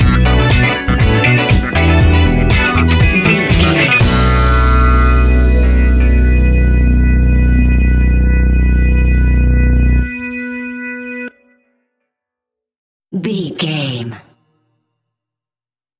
Aeolian/Minor
groovy
futuristic
hypnotic
uplifting
drum machine
synthesiser
electric guitar
electric piano
funky house
disco house
electronic funk
energetic
upbeat
synth leads
synth bass